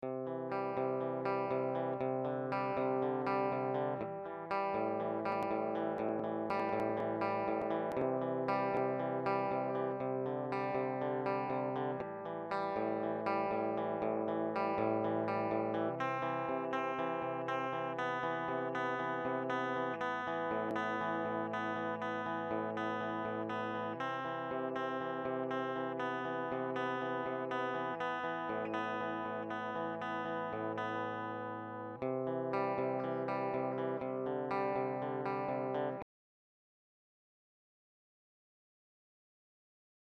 Can anyone identify whats causing this crackling sound?
Im very new to recording and use a Line 6 GX interface and Nuendo 4. if you listen to the click below you can here a crackling break up of sound near the start of the recording. If i try to record guitar it normally does this atleast once a minute and its seriously annoying me.